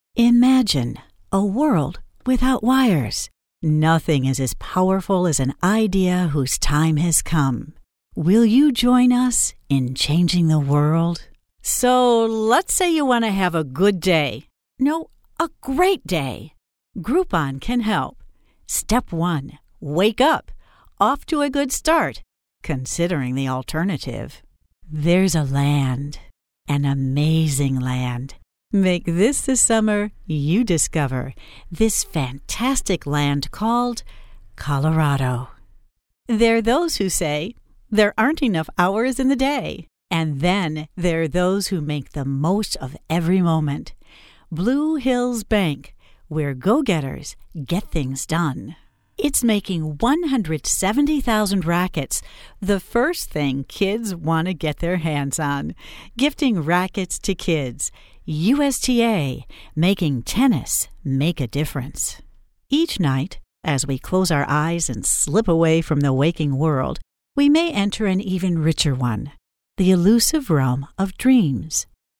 Female
English (North American)
Adult (30-50)
Looking for a voice that's friendly, warm and conversational, yet authoritative?
Main Demo
Versatile & Professional